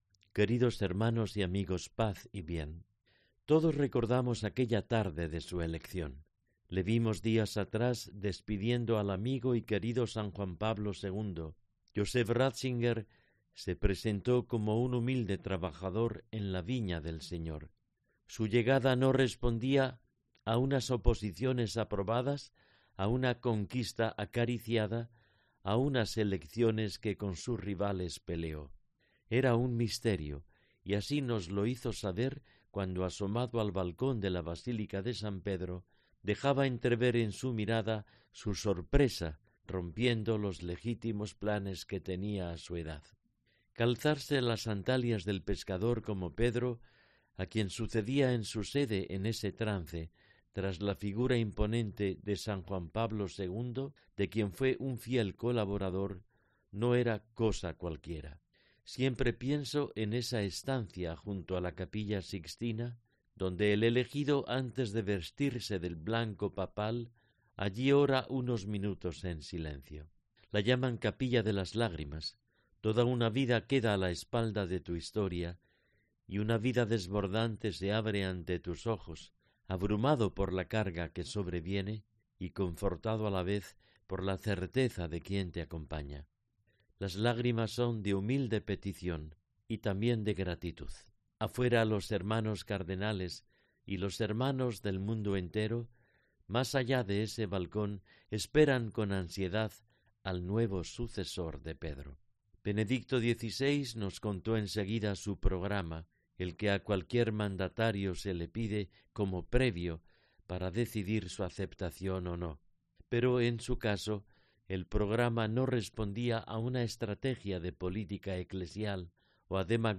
Mons. Jesús Sanz Montes glosa la figura de Benedicto XVI